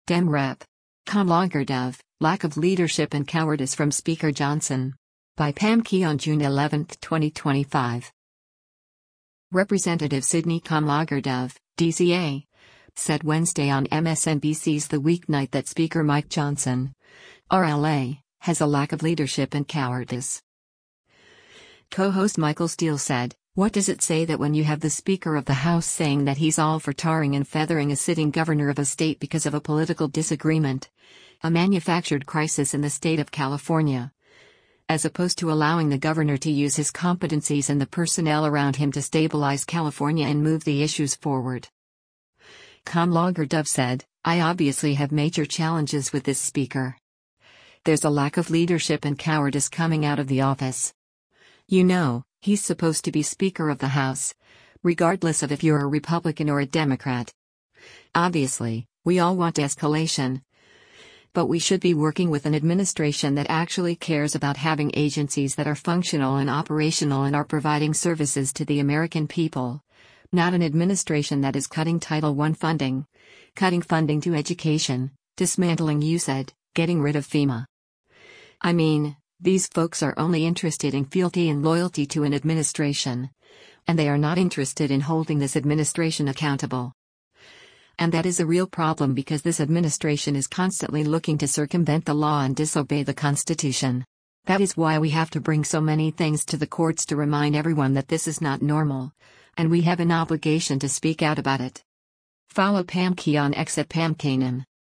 Representative Sydney Kamlager-Dove (D-CA) said Wednesday on MSNBC’s “The Weeknight” that Speaker Mike Johnson (R-LA) has a “lack of leadership and cowardice.”